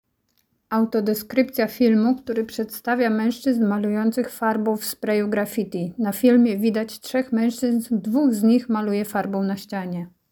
Nagranie audio Autodeskrypcja do filmu Grafficiarze z narkotykami przyłapani na gorącym uczynku